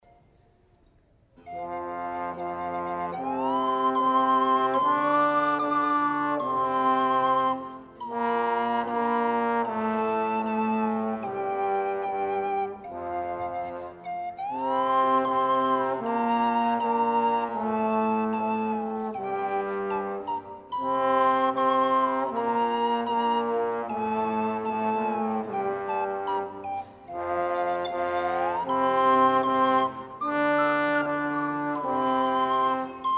This is a live recording of that magical afternoon.